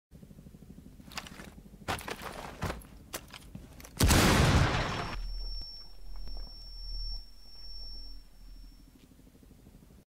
Breaching R6 Sound Button - Free Download & Play
Games Soundboard5 views